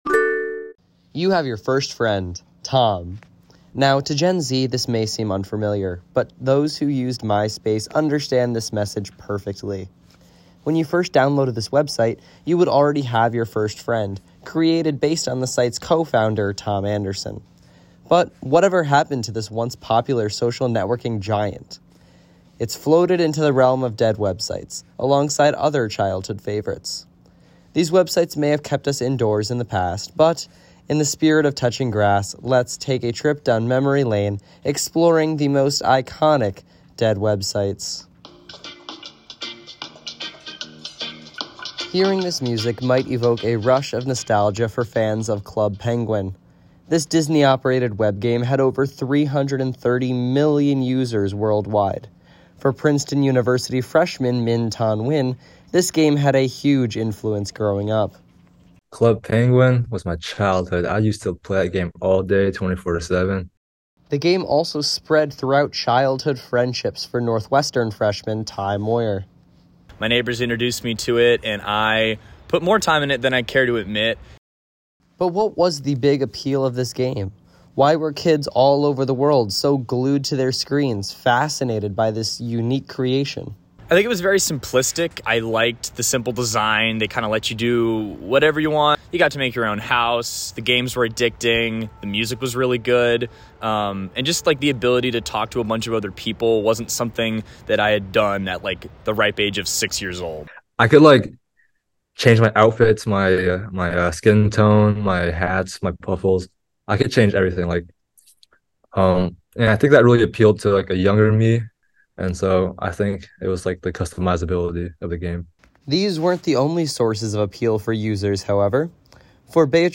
This story originally aired as part of our WNUR News Touches Grass Special Broadcast.